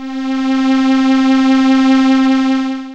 HI ARP STRS.wav